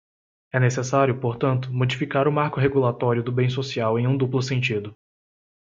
/mo.d͡ʒi.fiˈka(ʁ)/